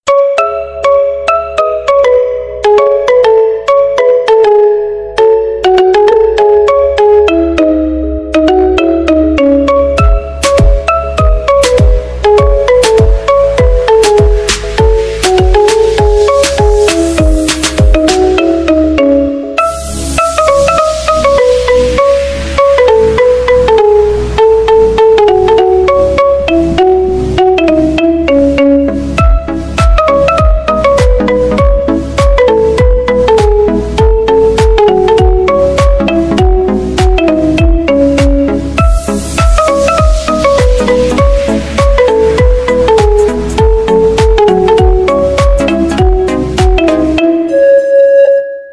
iOS, iPhone, Android, Remix, Ringtones